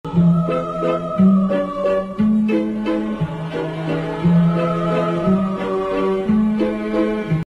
Szukam piosenki klasycznej